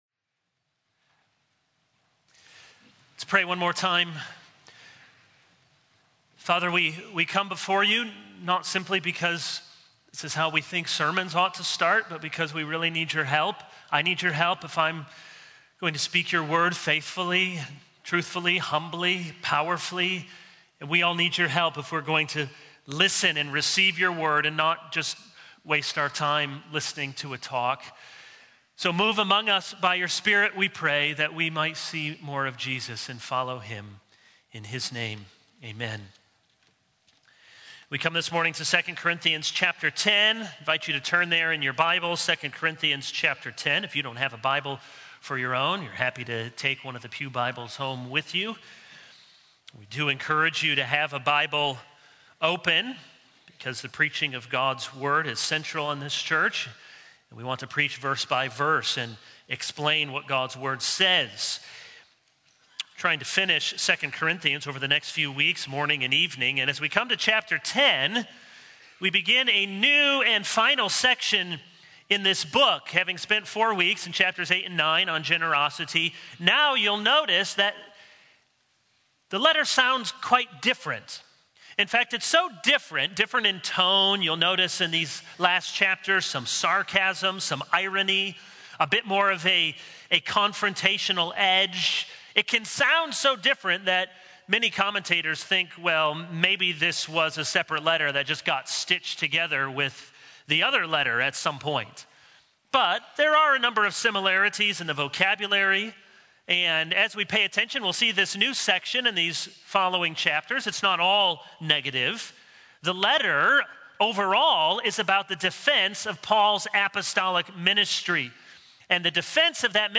This is a sermon on 2 Corinthians 10:1-6.